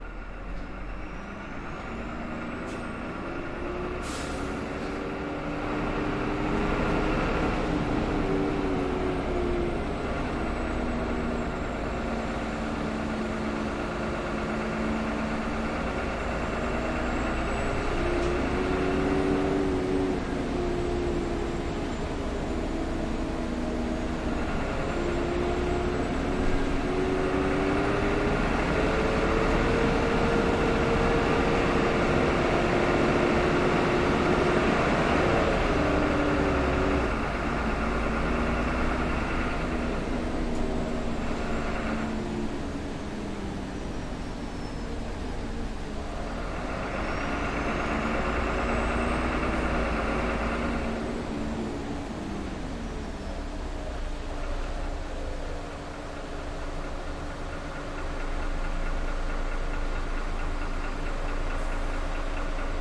道路街头的声音
描述：道路街头连续不断的声音，街头噪音。
标签： 街头 环境音 噪音 汽车
声道立体声